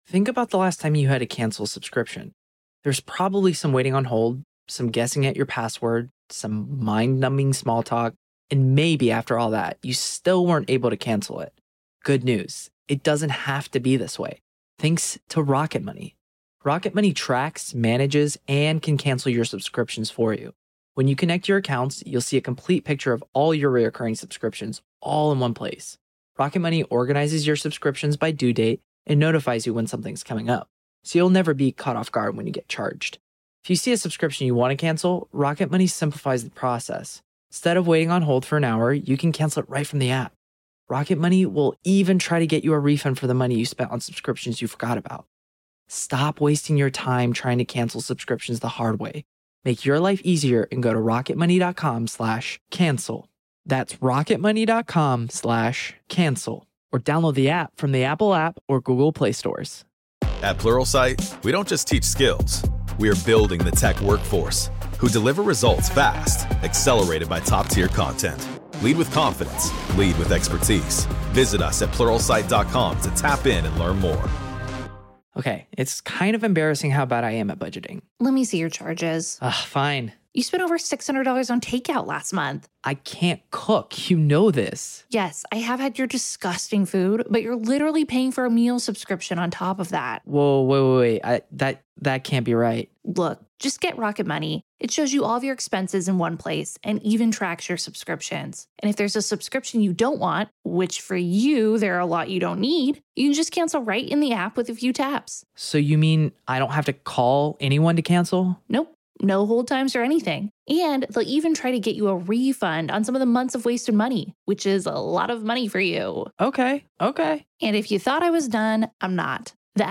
We step inside Charleston’s most infamous holding place to examine the lives it touched, the shadows it kept, and the lingering question: when a building has witnessed this much suffering, does the past ever truly let go? This is Part Two of our conversation.